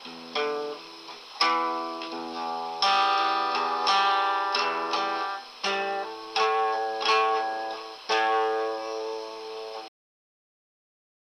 描述：磁带声
Tag: 85 bpm Acoustic Loops Guitar Acoustic Loops 1.90 MB wav Key : Unknown